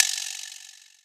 Perc 10 [ drill ].wav